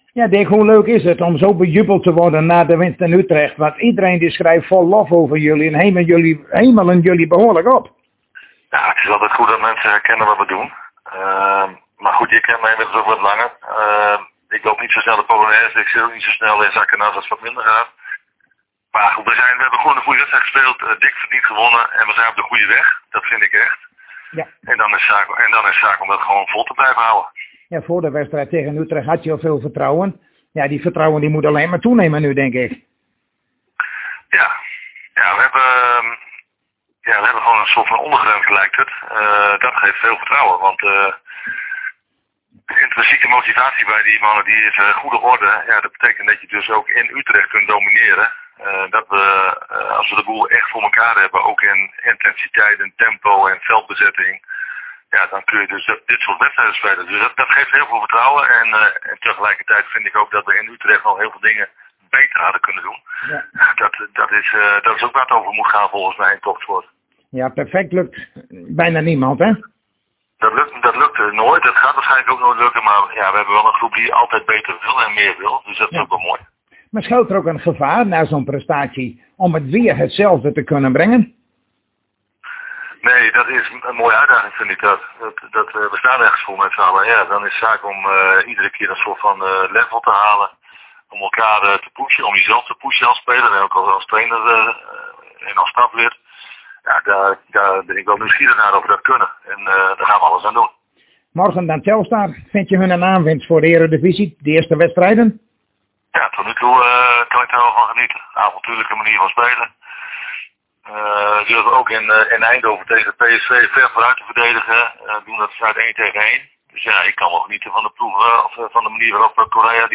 Zojuist spraken wij weer met trainer Dick Lukkien van FC Groningen over de wedstrijd van morgen tegen Telstar en legt hij nogmaals uit hoe de fitheid van Oscar Zawada is.